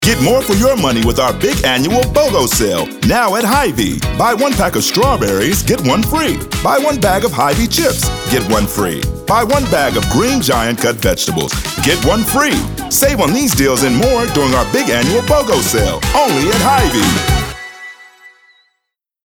His dynamic range and caring tone make him a compelling choice for any brand looking to connect with its audience.
African American, announcer, authoritative, Booming, confident, Deep Voice, friendly, genuine, gravelly, Gravitas, high-energy, informative, middle-age, midlife, perky, professional, retail, upbeat